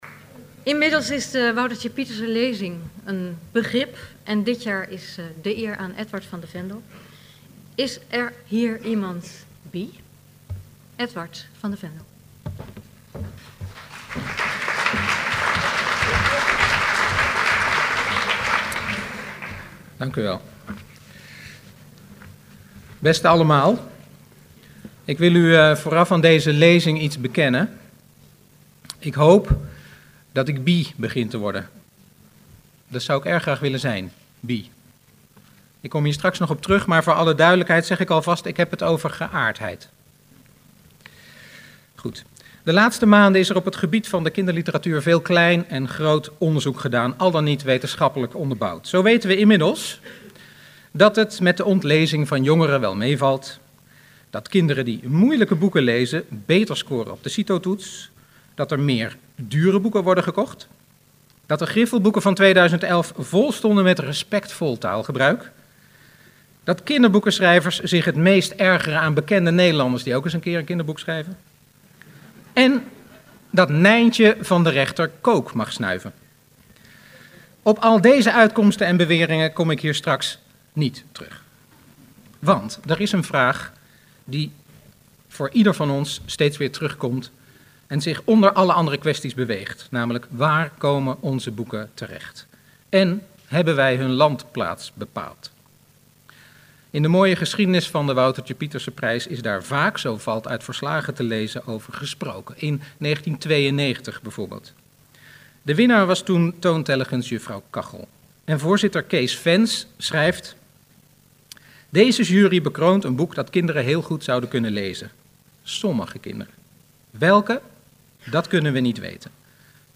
Woutertje Pieterse lezing 2012